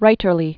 (rītər-lē)